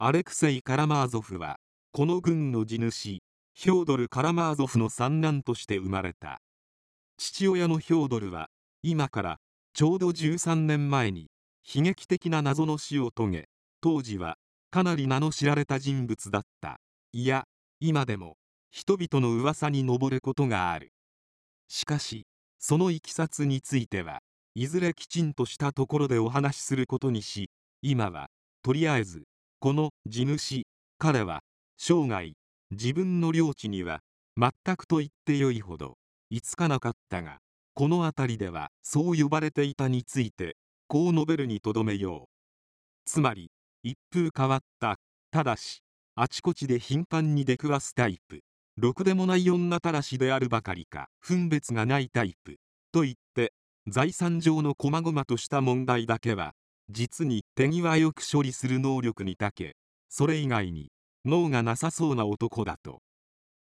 電子書籍や、音声朗読機能を使ってみるのも、良さそうなので、試しに作ってみました。
関西弁